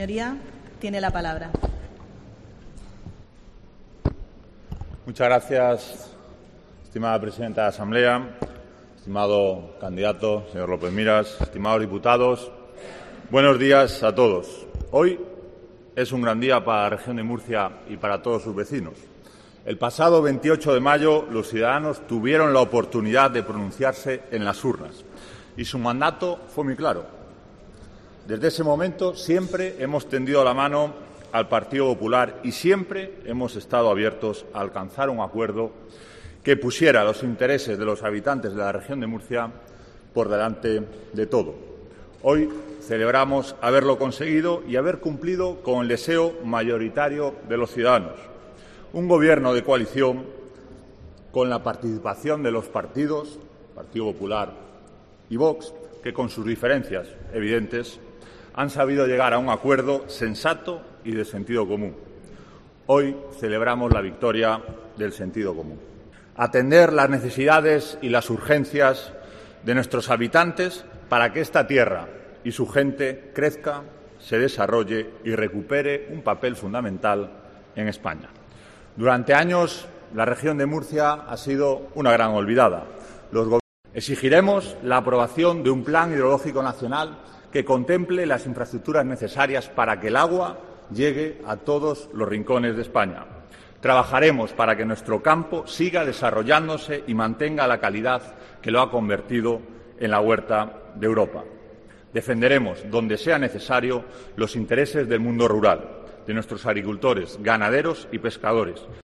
José Ángel Antelo, portavoz de VOX en Asamblea Regional
Lo ha dicho este jueves durante la segunda sesión del debate, en el que previsiblemente el popular Fernando López Miras será investido presidente de la comunidad autónoma tras el pacto suscrito con el partido ultraconservador, que ocupará las carteras de Fomento y de Seguridad, Interior y Emergencias, con rango de vicepresidencia, en el Ejecutivo autonómico.